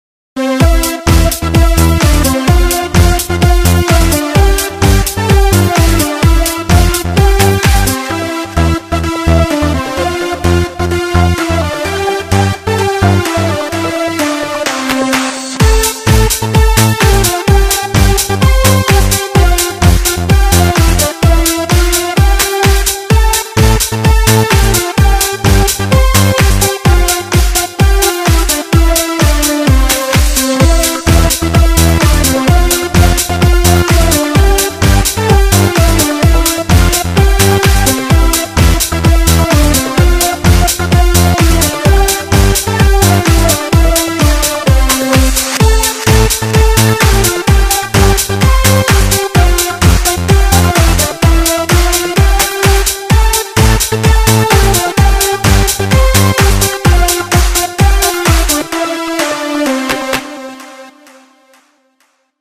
פלייבק של שירי יומולדת
אשמח אם יש למישהו פלייבק קצר של שיר/י יומולדת (ללא מילים, לא קריוקי)
היום_יום_הולדת-מנגינה.mp3